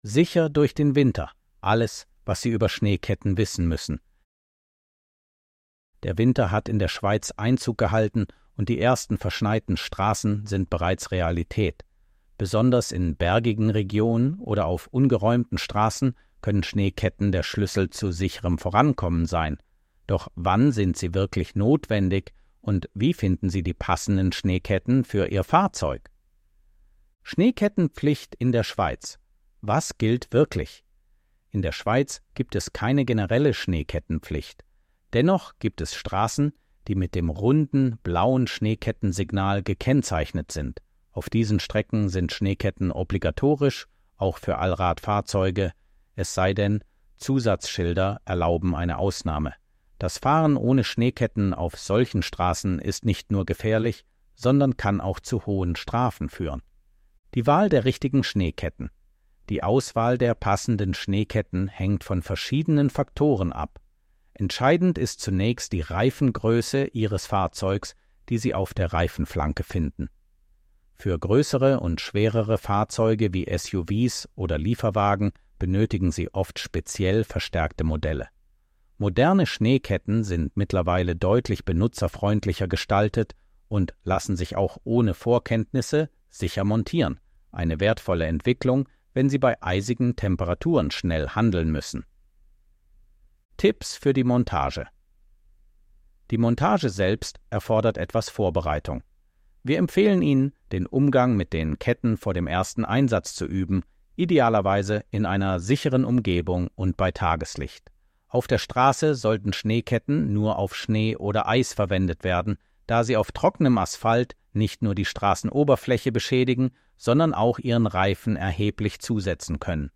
ElevenLabs_Chapter_0-1.mp3